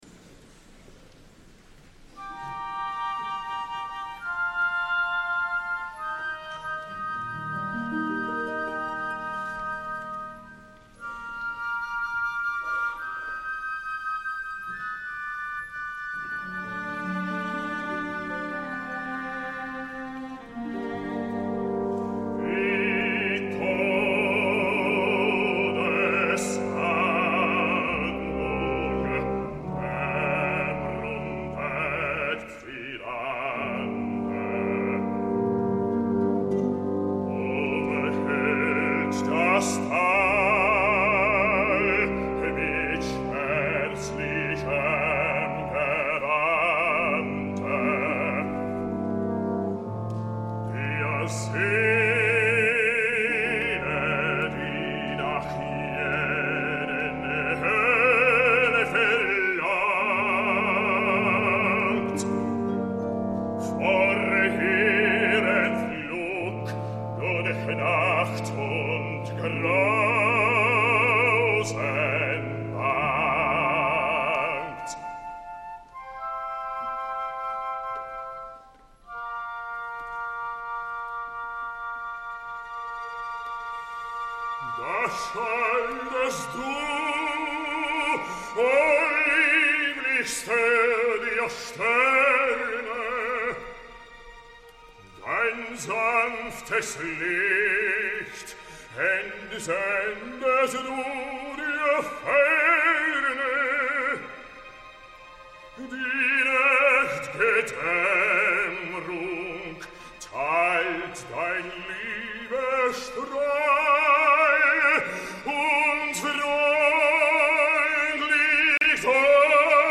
Avery Fisher Hall (Lincoln Center de Nova York)
director Membres de l’Orquestra del Metropolitan Opera House Director